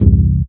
Звуки отмены для монтажа